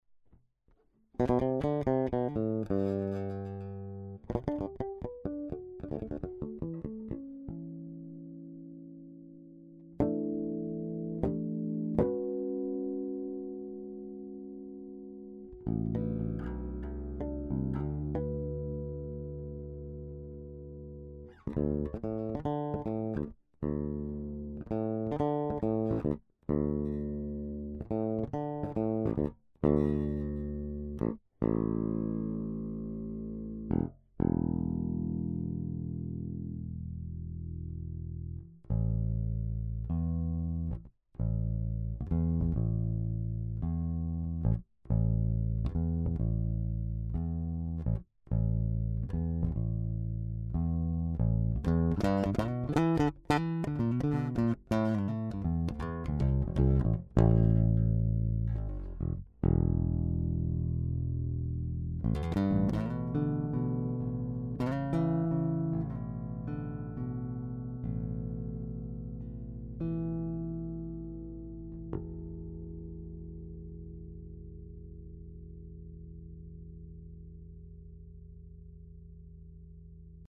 Ein für mich ungewohnter Sound Anteil ist für mich erkennbar.
Zum fragwürdigen Vergleich hab ich meinen Fender Classic 60 mit aktiven EMG und einer Fender Vintage Bridge aufgenommen. Gleicher Bass, gleiche Swing Bass Saiten von Rotosound und der Hipshot KickAss in der zweiten Aufnahme.
Für fetten Bass ist die Vintage für mich auf jeden Fall besser.